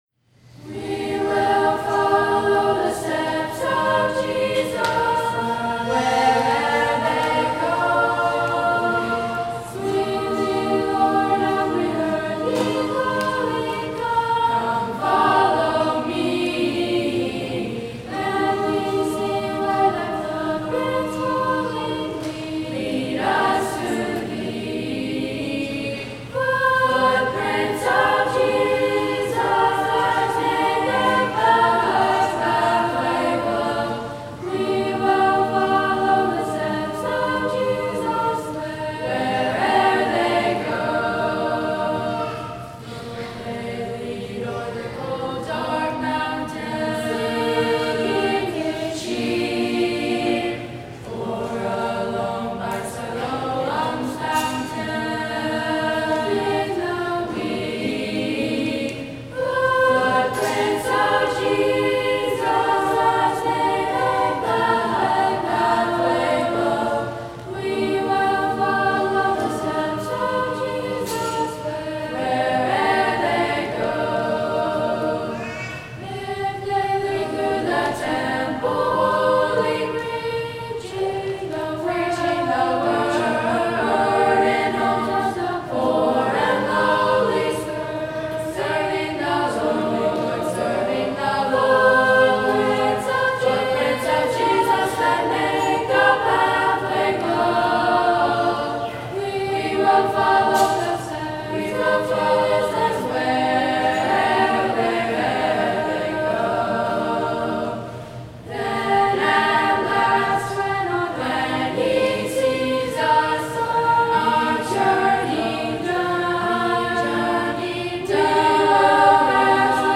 Voicing: SAT(B), a cappella